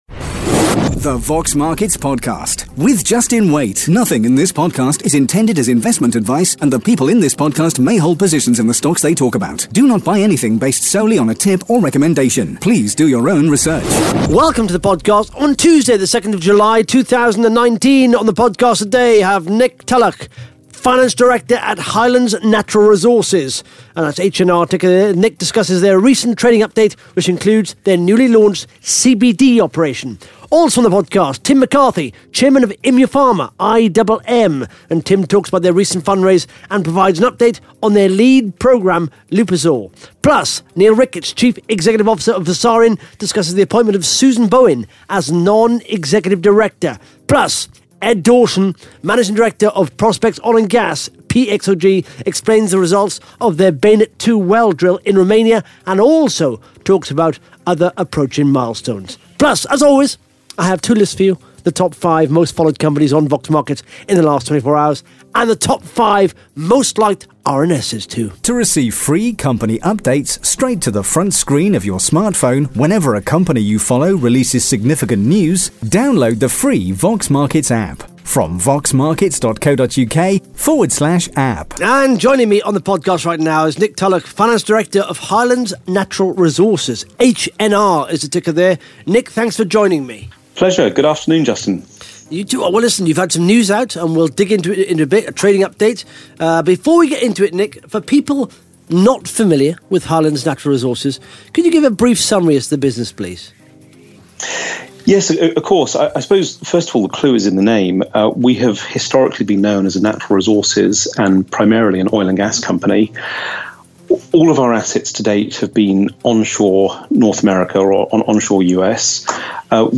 (Interview starts at 12 minutes 56 seconds)